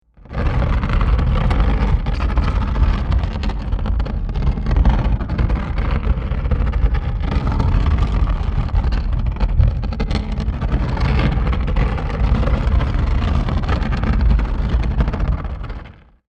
Wood Scrape; Heavy Wooden Object Drags Across Rough Surface With Rumble.